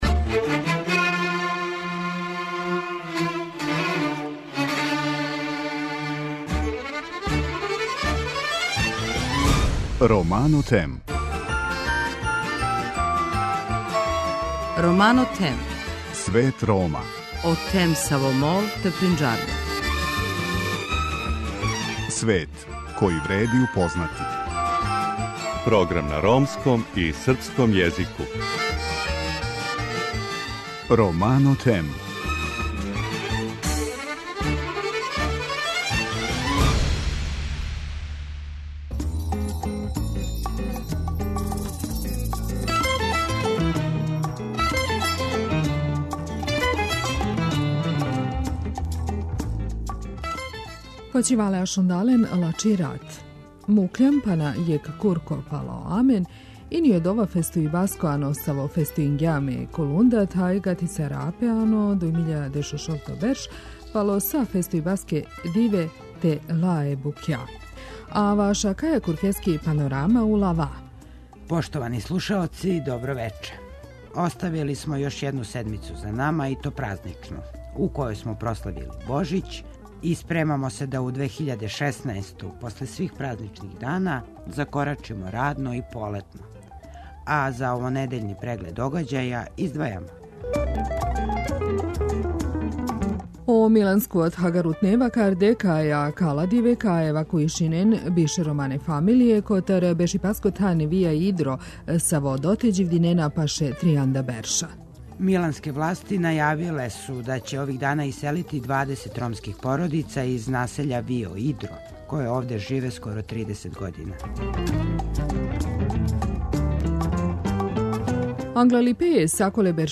Гошћа вечерашње емисије је Сузана Пауновић директорка владине канцеларије за људска и мањинска права која говори шта је све урађено на унапређењу положаја ромске заједнице у 2015. години.